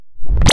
bay_door_open.wav